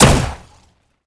sci-fi_weapon_plasma_pistol_04.wav